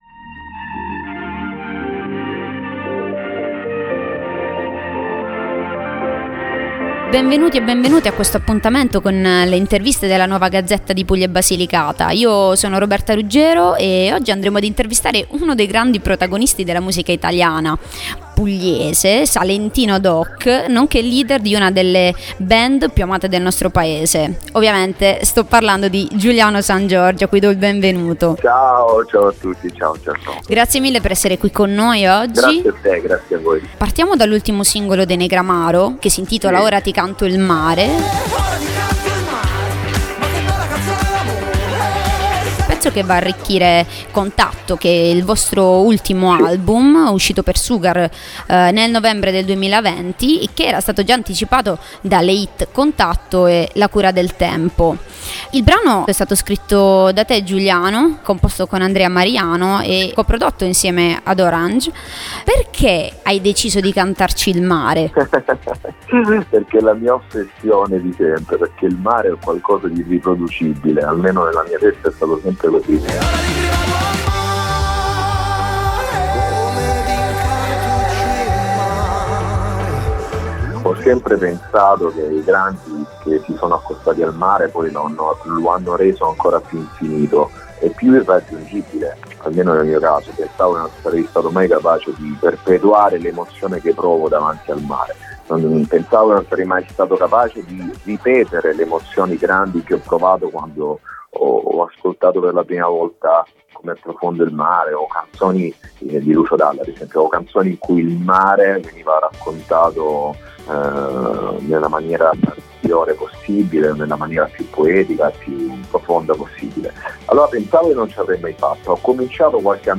Ep2 - Intervista con Giuliano Sangiorgi
Intervista-con-Giuliano-Sangiorgi.mp3